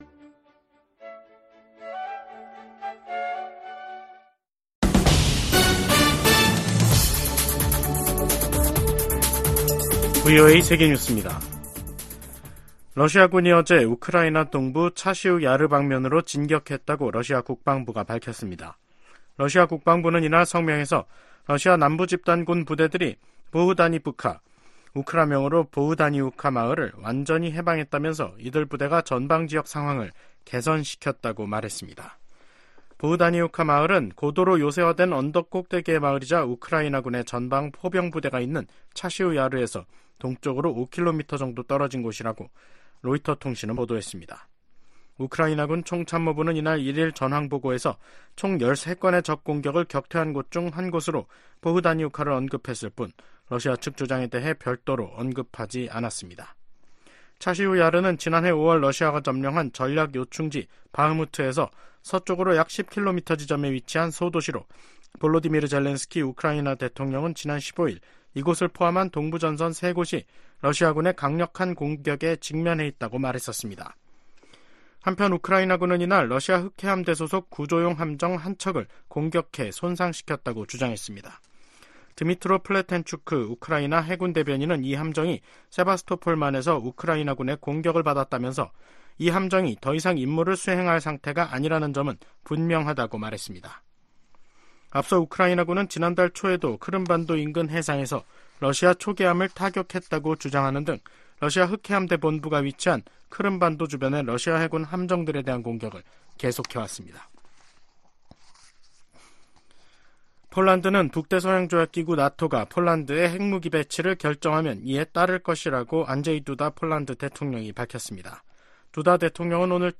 VOA 한국어 간판 뉴스 프로그램 '뉴스 투데이', 2024년 4월 22일 2부 방송입니다. 북한이 순항미사일과 지대공 미사일을 시험발사한 지 3일만에 다시 초대형 방사포로 추정되는 단거리 탄도미사일을 발사했습니다. 토니 블링컨 미 국무장관은 북한과 러시아 간 무기 이전 문제를 지적하며 주요 7개국, (G7)이 이를 막기 위한 공동 노력을 강화하고 있다고 밝혔습니다.